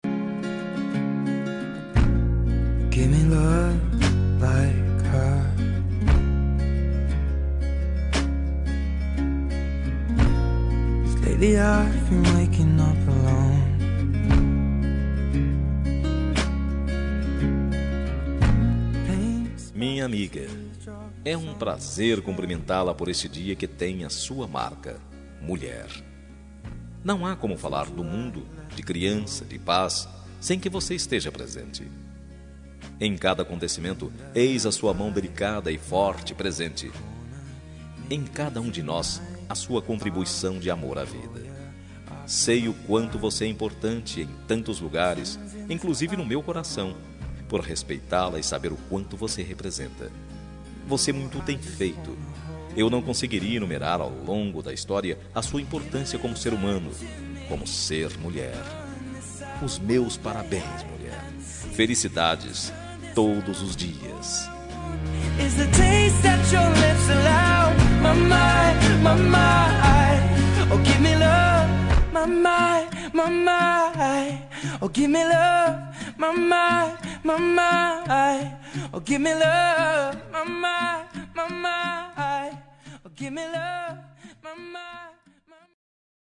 Dia das Mulheres Para Amiga – Voz Masculina – Cód: 5355 – Linda